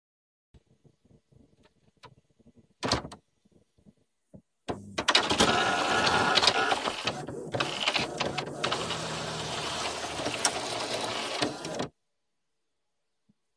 На этой странице собраны звуки работающего принтера: от монотонного жужжания лазерных моделей до характерного треска матричных устройств.
Принтер запустил печать